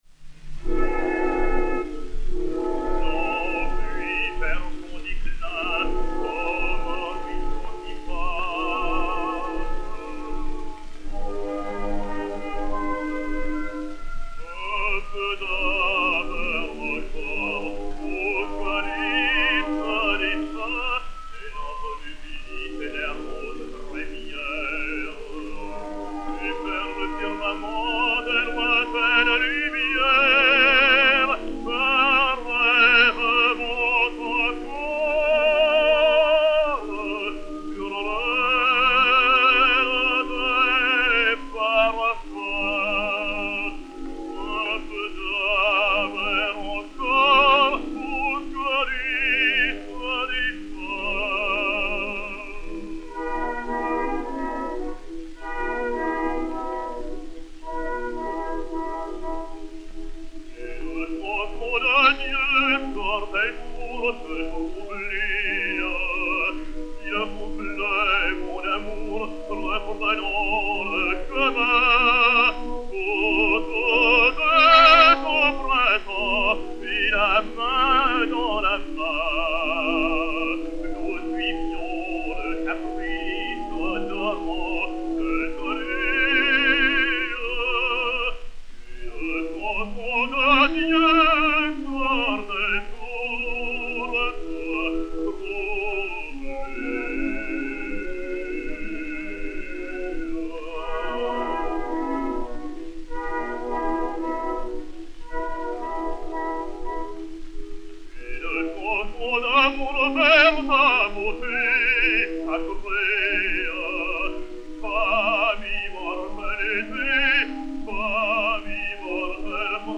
Daniel Vigneau, baryton, avec orchestre